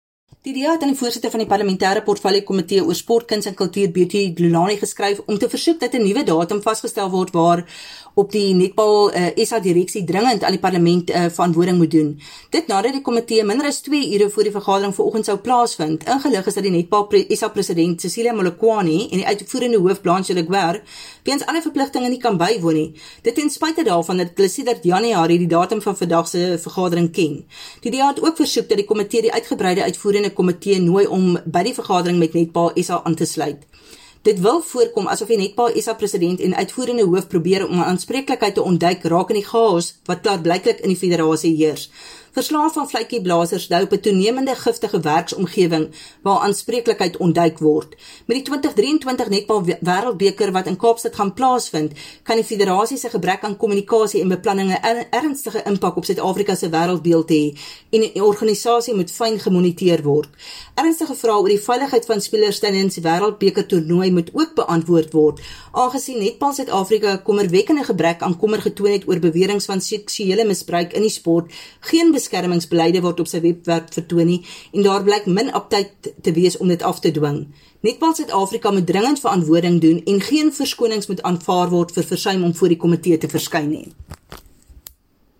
Afrikaans soundbites by Veronica van Dyk MP.